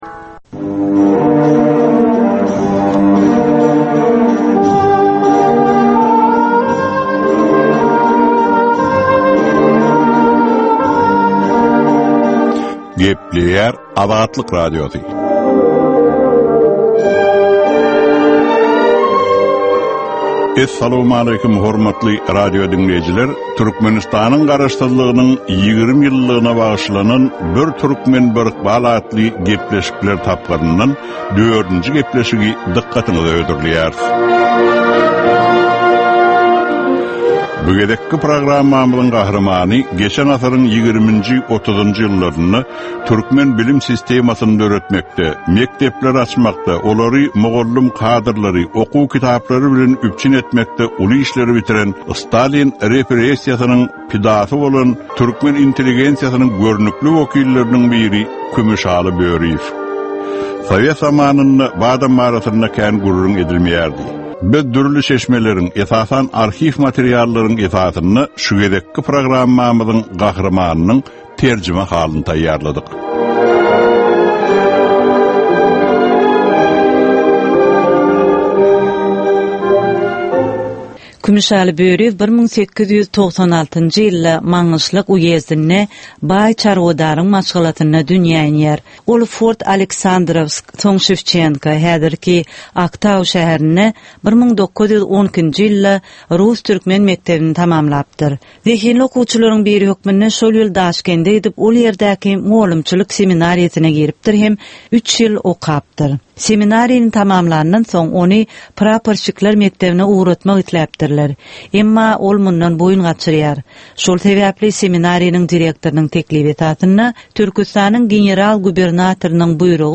Türkmenistan we türkmen halky bilen ykbaly baglanyşykly görnükli şahsyýetleriň ömri we işleri barada ýörite gepleşik. Bu gepleşikde gürrüňi edilýän gahrymanyň ömri we işleri barada giňişleýin arhiw materiallary, dürli kärdäki adamlaryň, synçylaryň, bilermenleriň, žurnalistleriň we ýazyjy-sahyrlaryň pikirleri, ýatlamalary we maglumatlary berilýär.